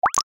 Звуки бульканья